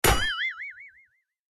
wood_hit_01.ogg